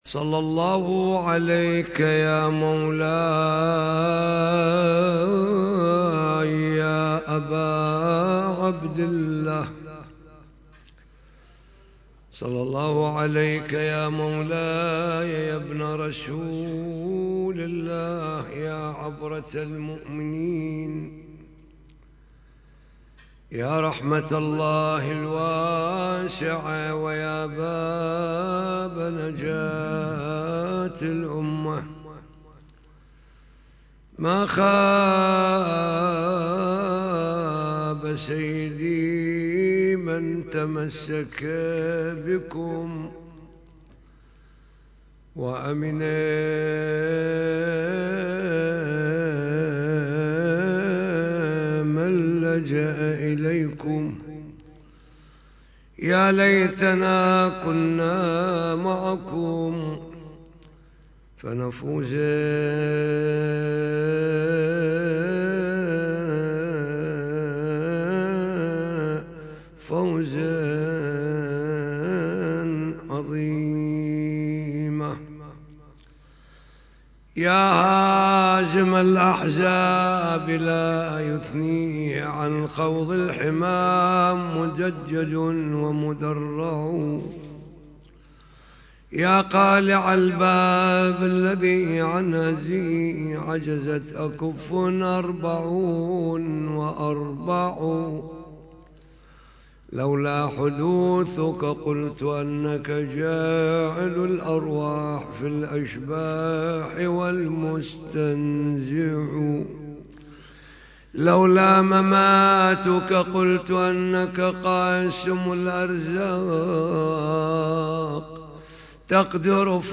محاضرة ليلة 1 جمادى الثاني